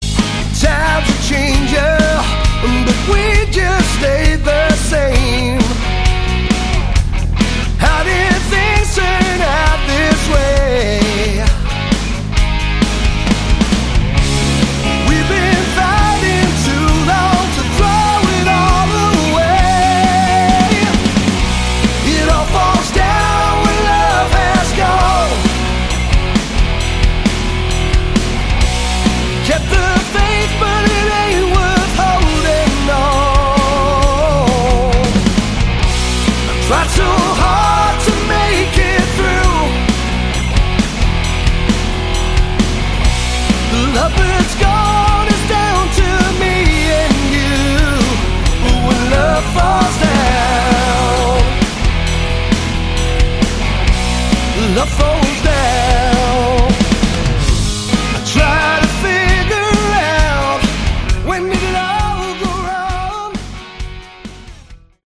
Category: Hard Rock
lead and backing vocals, bass, guitars
electric, acoustic and slide guitars
drums
kayboards, backing vocals